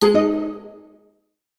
accept answer bonus correct ding good notification ok sound effect free sound royalty free Sound Effects